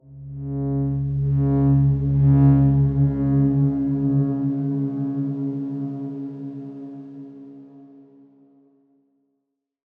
X_Darkswarm-C#2-pp.wav